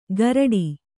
♪ garaḍi